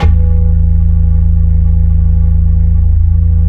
PERC FLUTE-R.wav